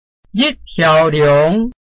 臺灣客語拼音學習網-客語聽讀拼-饒平腔-鼻尾韻
拼音查詢：【饒平腔】liung ~請點選不同聲調拼音聽聽看!(例字漢字部分屬參考性質)